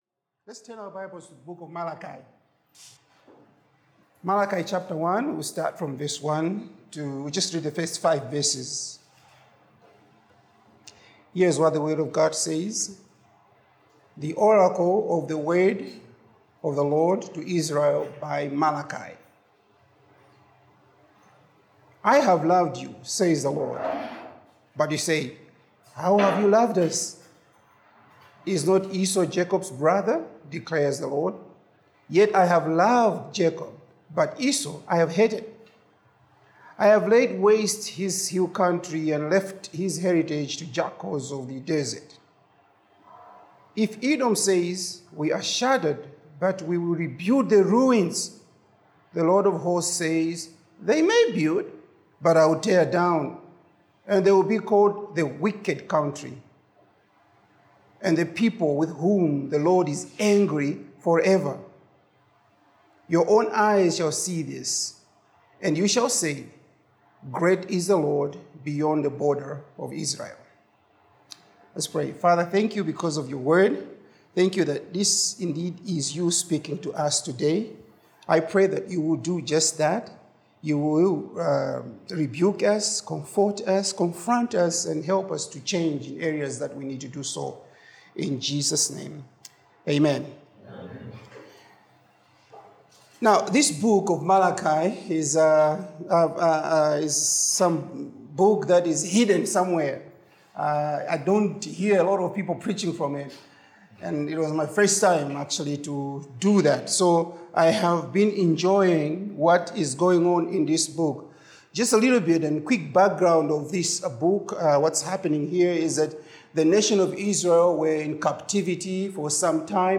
In this sermon on Malachi 1:1-5, we’re reminded of God’s unwavering love—even when His people doubt and grow complacent.